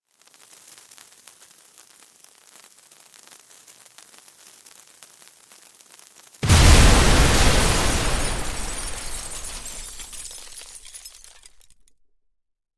Звук горящего фитиля динамитной бомбы